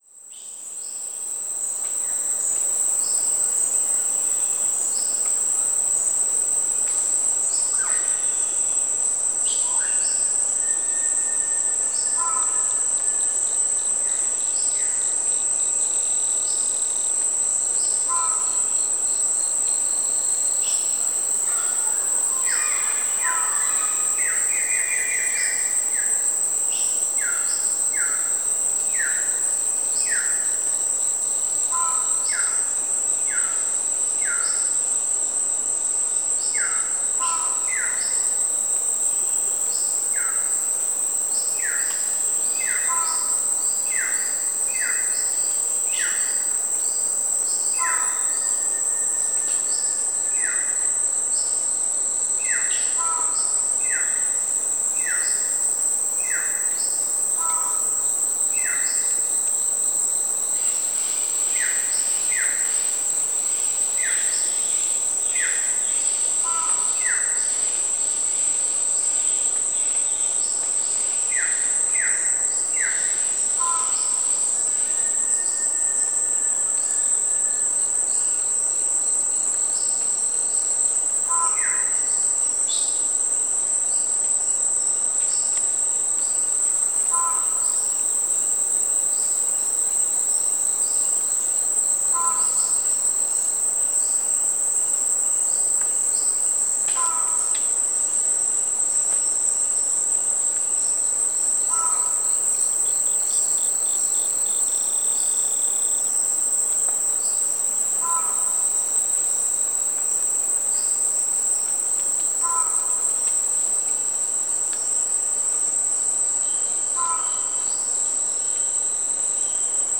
Soundbeispiel 2, Darién, 18.02.2019 Trockenzeit etwa 09.00 morgens Ortszeit. Verschiedene Vogelrufe, Insekten (u.a. Zikaden), Frösche, das Rauschen eines Flusses im Hintergrund.